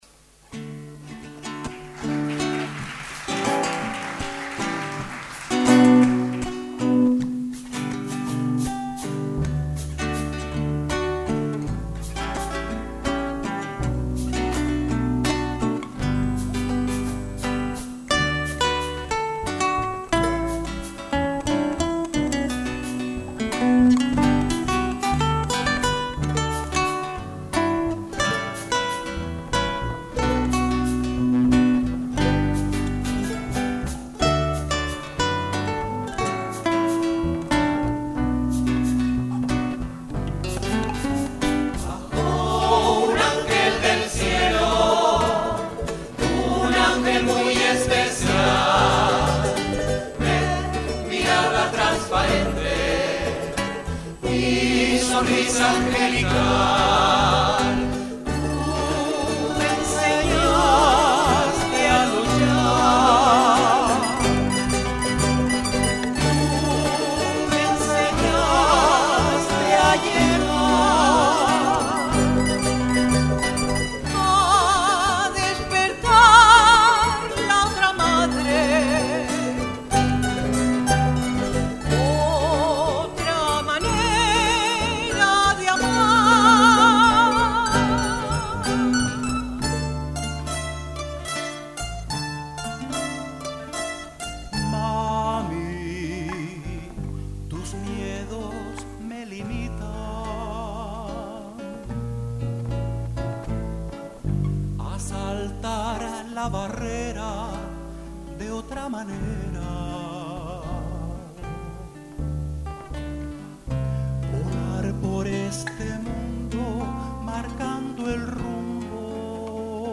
El jueves 16 de mayo, de 19:00 a 20:30 horas, se celebró en el salón de actos de la Facultad de Formación del Profesorado, con la participación del grupo "LasPalmeños ULPGC", siendo además su presentación oficial en la universidad con el nuevo nombre, y con nuevos temas.